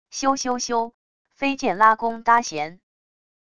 咻咻咻……飞箭拉弓搭弦wav音频